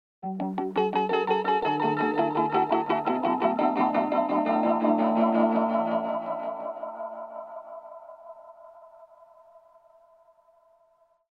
• Taps＋Portalを使用
このように、Tapsの多彩なディレイパターン、Portalsの複雑なサウンド、そして両者と他ノードを組み合わせた予測不可能なサウンド変化を構築することが可能です。